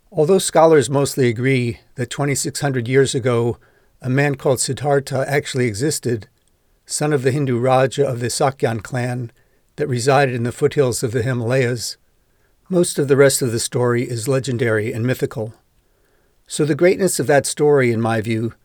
Apparently … that’s your normal … reading style.
I quickly patched a segment at 50 seconds and “took the air out of the speech.”
It doesn’t turn into normal flowing speech because you build start and stop vocal tone into almost every pause.
Some of the pauses are natural places to take a breath and I left them in.
Vocal tone overall is good and there are no sound probems that I could find.